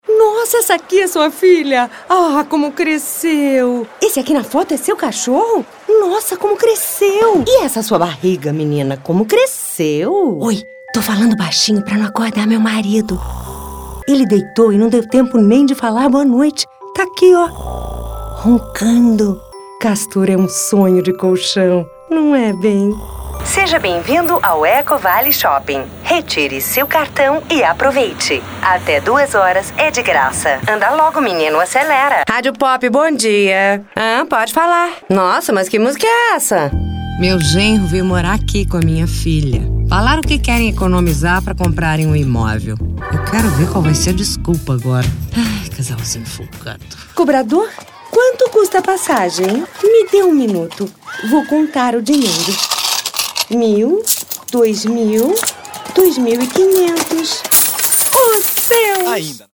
Brazilian native VO talent and actress with +20 years experience, records in BR Portuguese and English w/accents.
Sprechprobe: Sonstiges (Muttersprache):